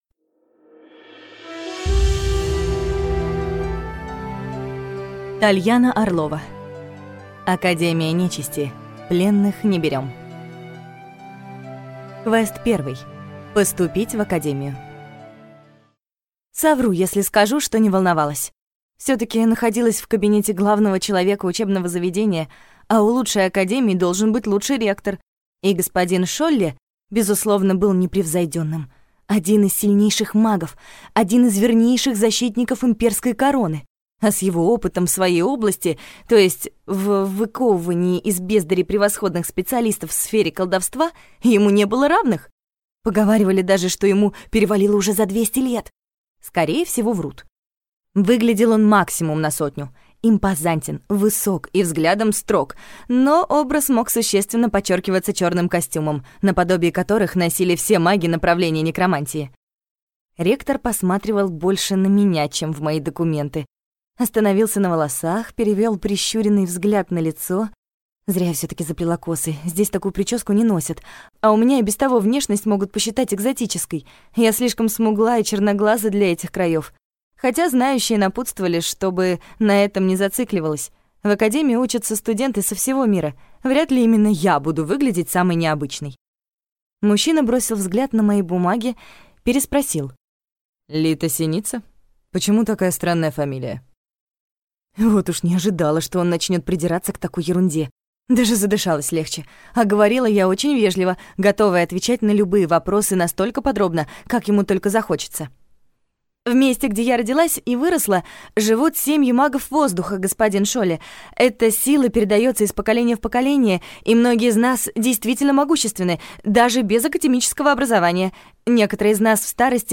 Аудиокнига Академия нечисти: пленных не берем | Библиотека аудиокниг